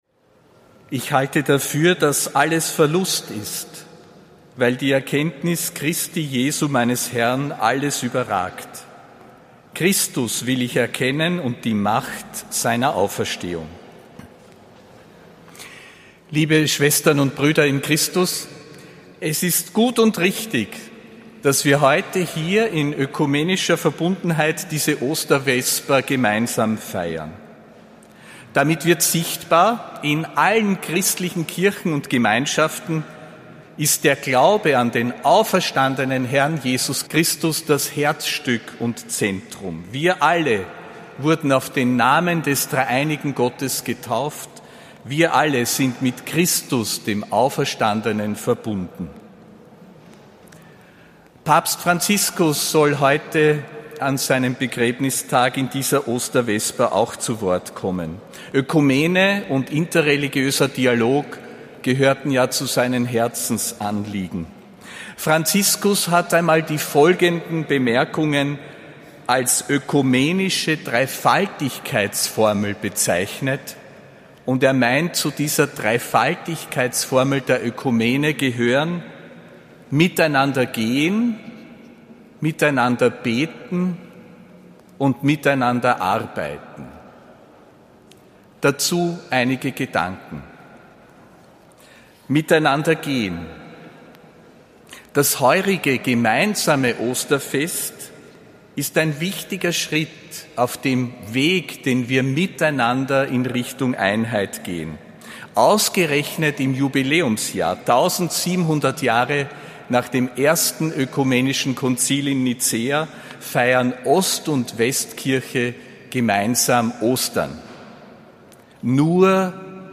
Predigt des Apostolischen Administrators Josef Grünwidl zur Ökumenischen Vesper, am 26. April 2025.